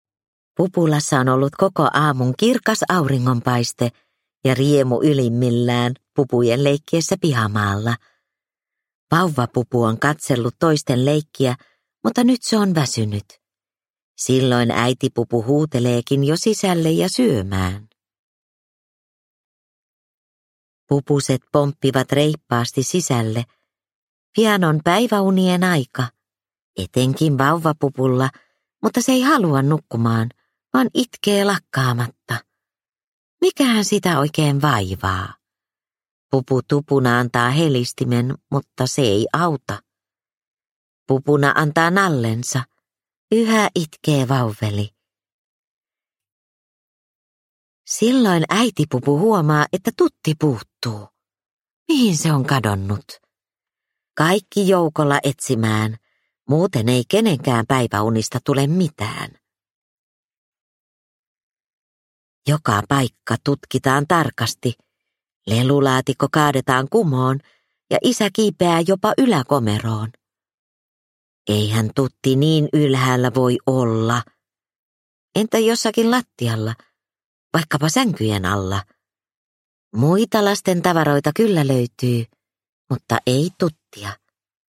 Pupu Tupuna - Tutti hukassa – Ljudbok – Laddas ner